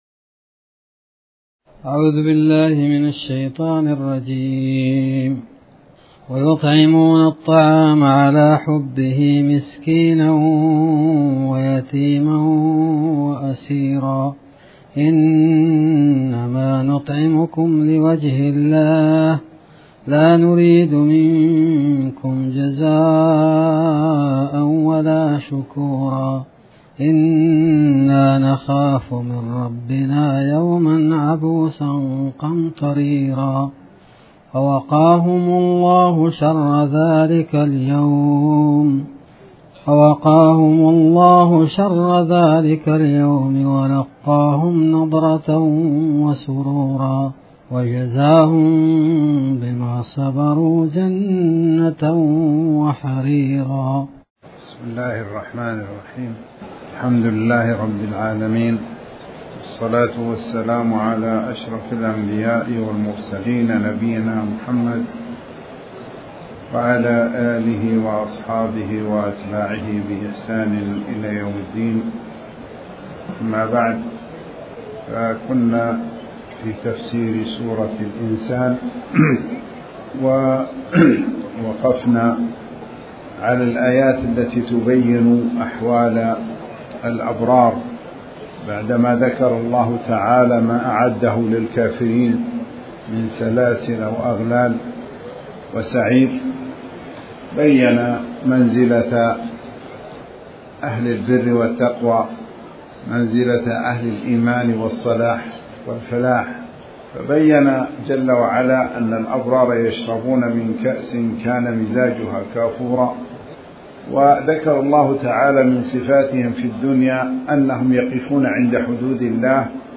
تاريخ النشر ٢٨ محرم ١٤٤٠ هـ المكان: المسجد الحرام الشيخ